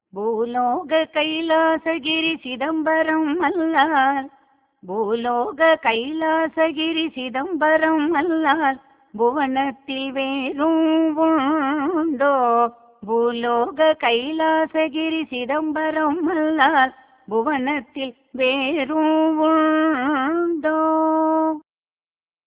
இராகம் : அபினா